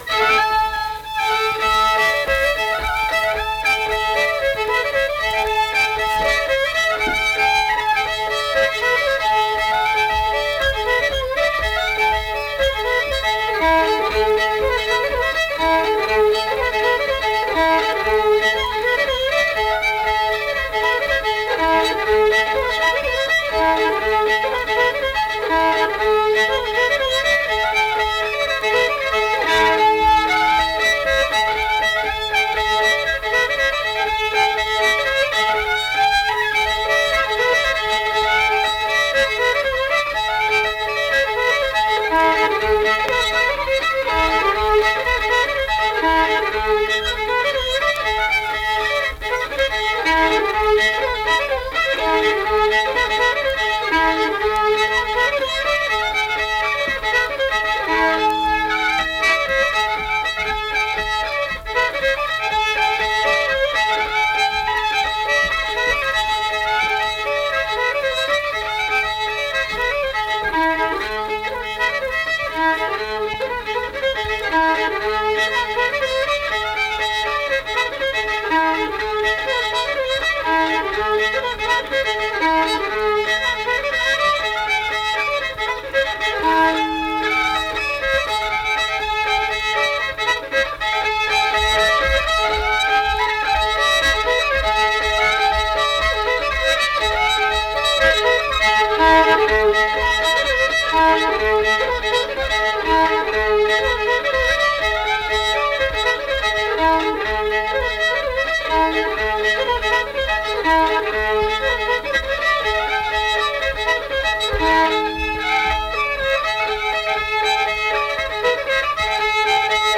Instrumental Music
Fiddle
Pocahontas County (W. Va.), Mill Point (W. Va.)